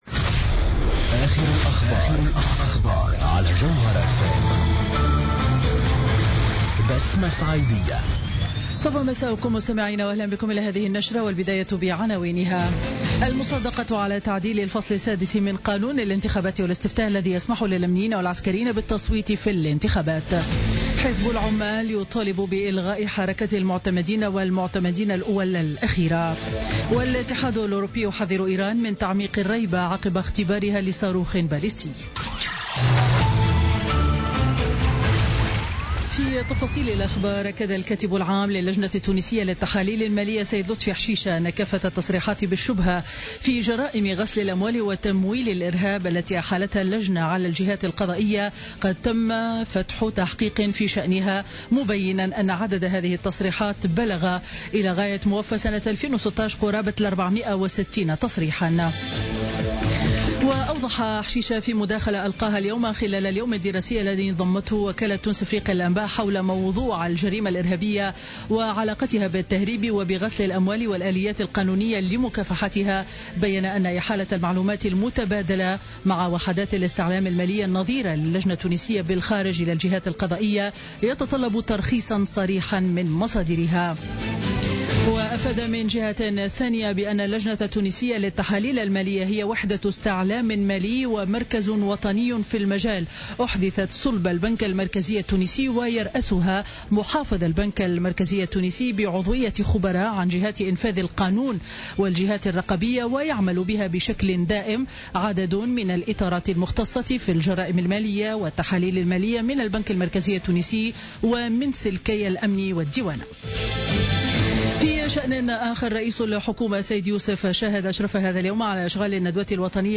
نشرة أخبار السابعة مساء ليوم الثلاثاء 31 جانفي 2017